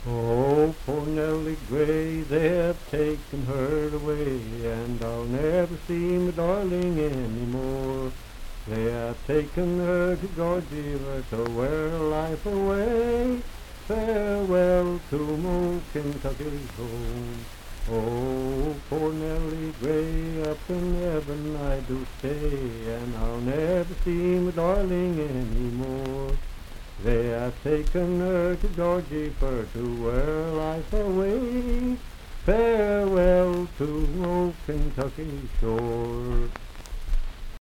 Unaccompanied vocal music
Voice (sung)
Pendleton County (W. Va.), Franklin (Pendleton County, W. Va.)